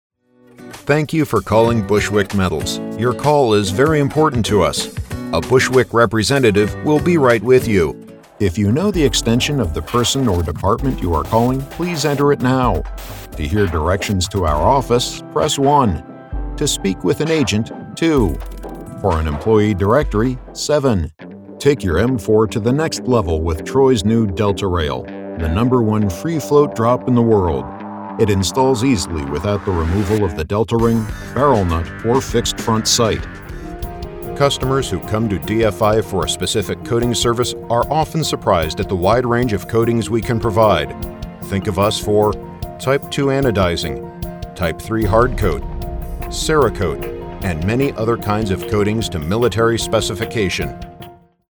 Anglais (Américain)
Commerciale, Profonde, Naturelle, Enjouée, Polyvalente
E-learning